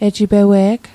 Pronunciation Guide: e·ji·be·weeg